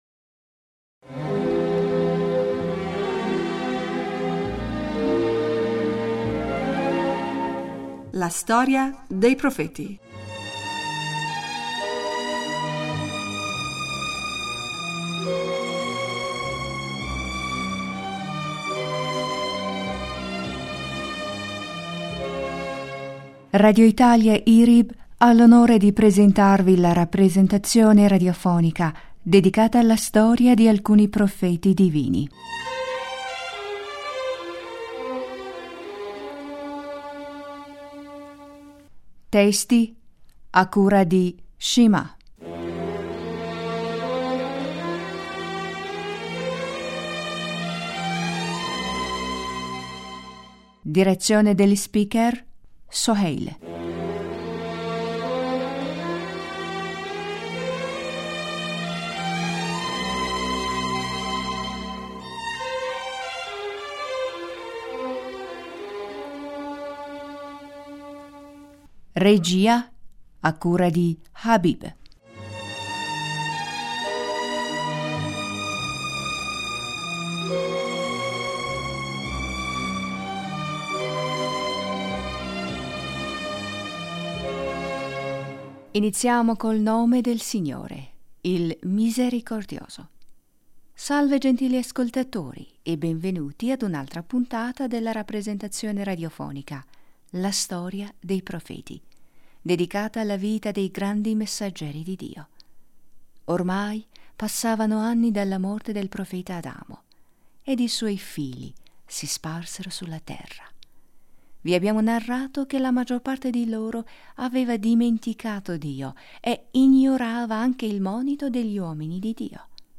Salve gentili ascoltatori e benvenuti ad una altra punta della rappresentazione radiofonica “La...